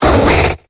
Cri de Goinfrex dans Pokémon Épée et Bouclier.